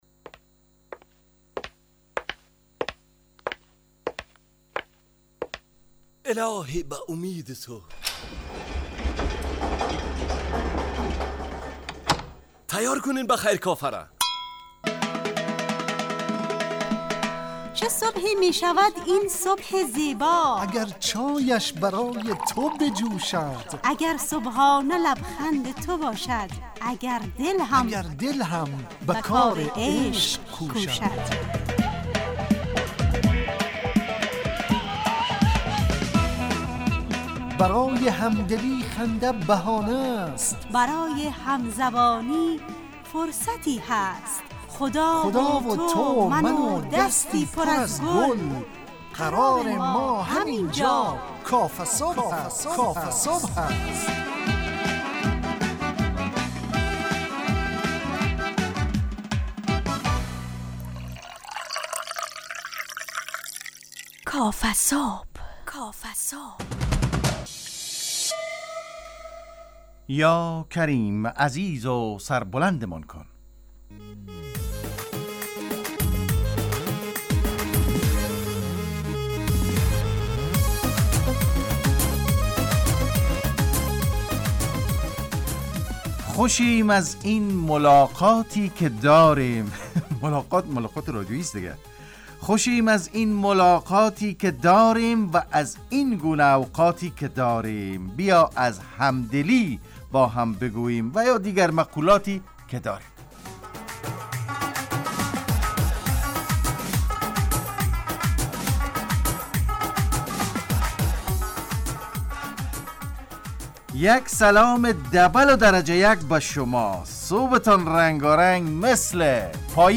کافه صبح - مجله ی صبحگاهی رادیو دری با هدف ایجاد فضای شاد و پرنشاط صبحگاهی همراه با طرح موضوعات اجتماعی، فرهنگی، اقتصادی جامعه افغانستان همراه با بخش های کارشناسی، نگاهی به سایت ها، گزارش، هواشناسی و صبح جامعه